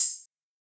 UHH_ElectroHatB_Hit-22.wav